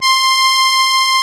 MUSETTE 1.17.wav